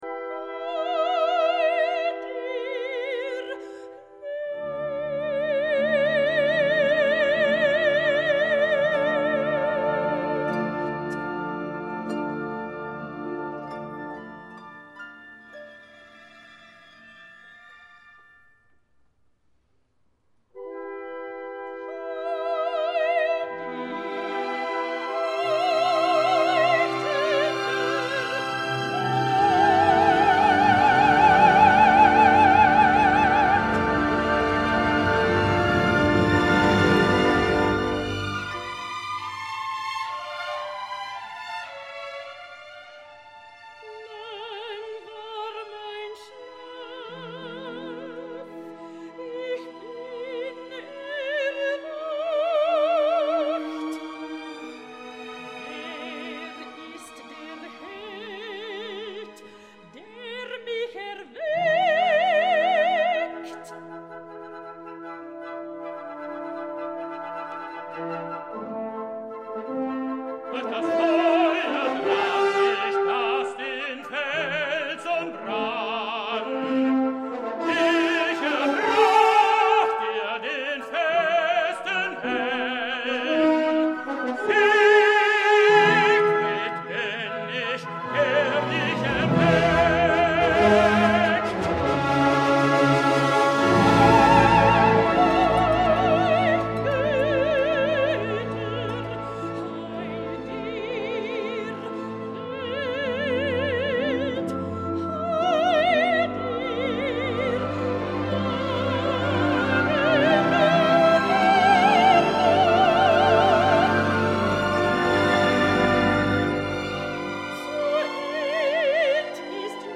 L’any 2013 va ser wagnerianament parlant aclaparador, la celebració del bicentenari del compositor ens va regalar diverses tetralogies i potser la de Bucarest, en versió de concert i dirigida…
Ara aquella Tetralogia ha agafat un renovat interès ja que el rol de Siegfried en les dues darreres jornades el va cantar Stefan Vinke, el que de la nit al dia per a tots els liceistes ha esdevingut la revelació de la temporada.
tenor alemany